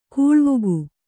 ♪ kūḷvugu